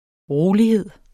Udtale [ ˈʁoːliˌheðˀ ]